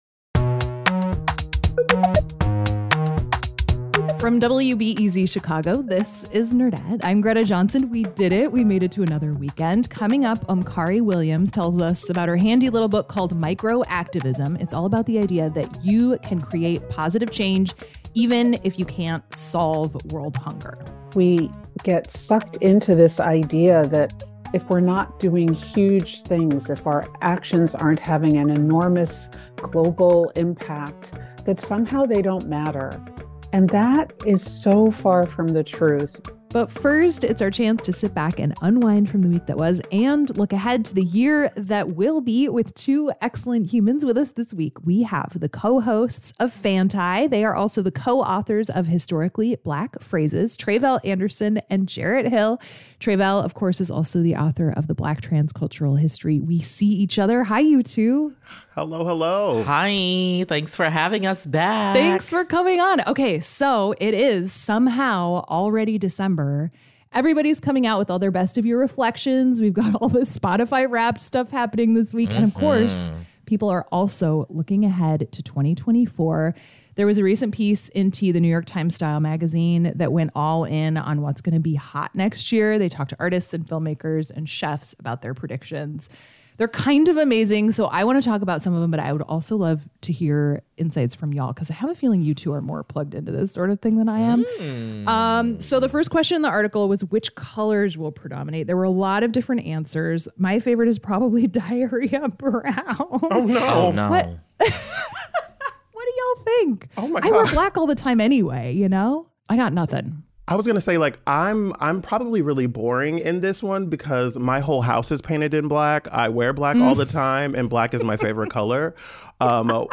The Nerdette Podcast on WBEZ Chicago (an NPR station) interview begins at 16:42